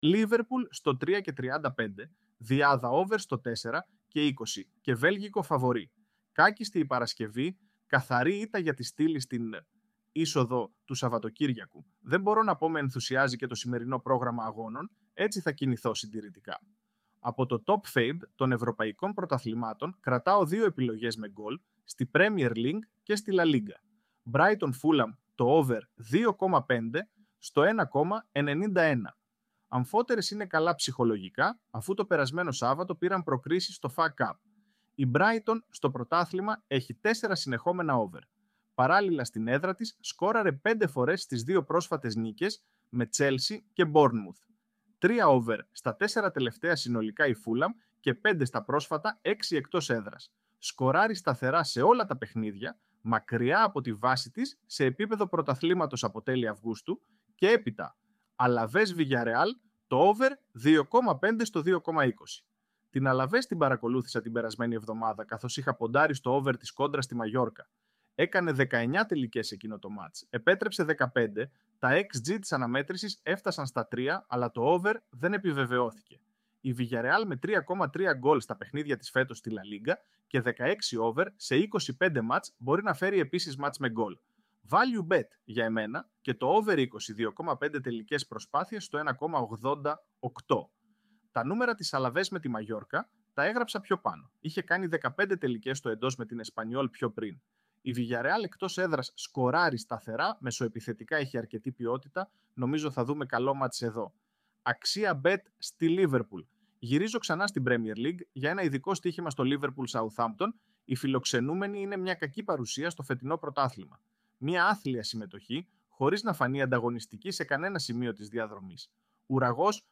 με τη βοήθεια του Ai!